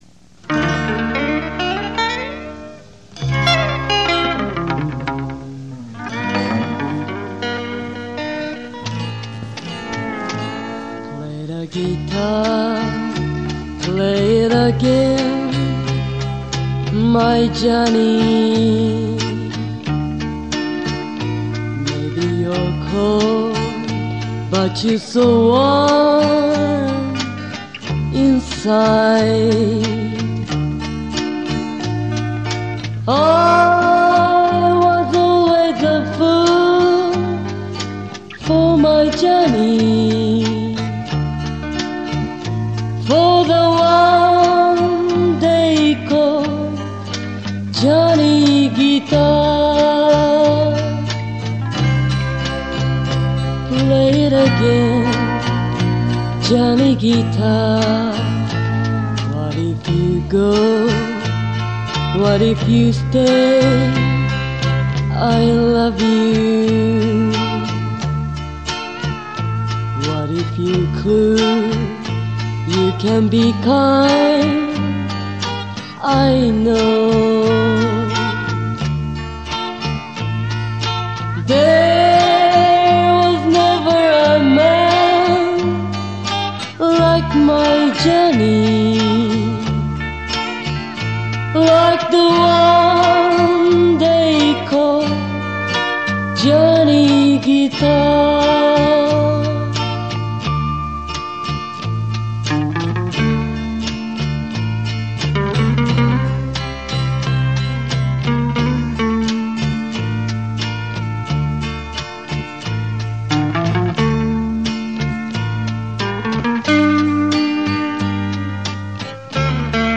Скорее всего исполнитель японка..